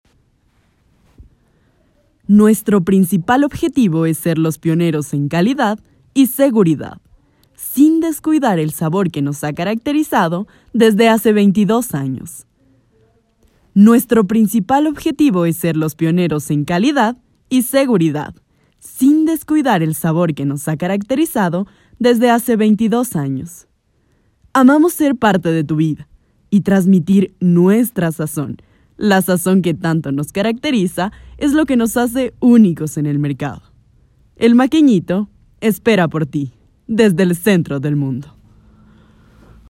Voz femenina joven.
spanisch Südamerika
Sprechprobe: Werbung (Muttersprache):
Young Woman voice.